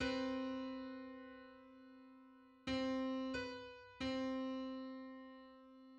File:Four-hundred-eighty-third harmonic on C.mid - Wikimedia Commons
Just: 483/256 = 1099.06 cents.
Public domain Public domain false false This media depicts a musical interval outside of a specific musical context.
Four-hundred-eighty-third_harmonic_on_C.mid.mp3